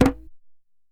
DJEM.HIT19.wav